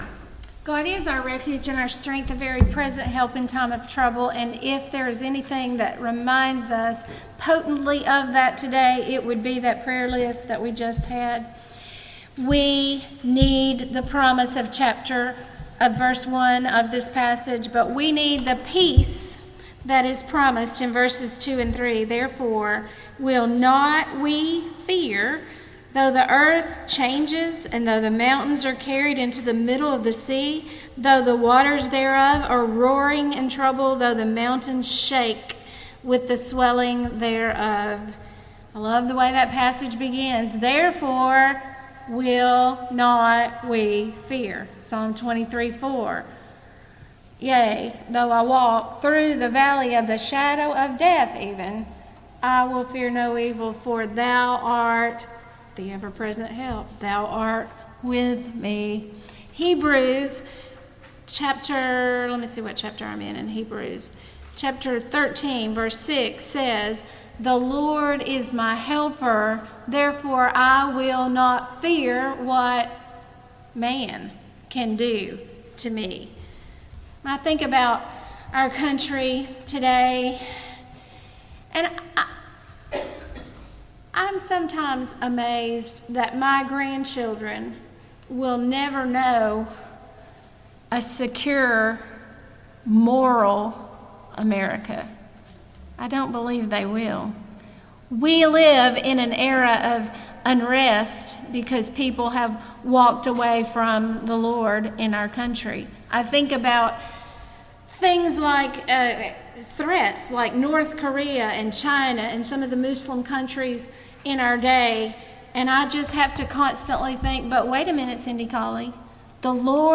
Passage: Psalm 46:8-11 Service Type: Ladies' Day